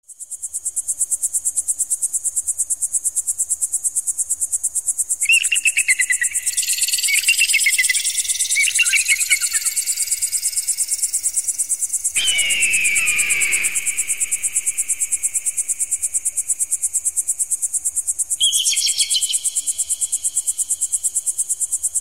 Эффективные звуки для отпугивания стай голубей